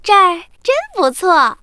文件 文件历史 文件用途 全域文件用途 Daphne_tk_03.ogg （Ogg Vorbis声音文件，长度0.0秒，0 bps，文件大小：23 KB） 源地址:游戏语音 文件历史 点击某个日期/时间查看对应时刻的文件。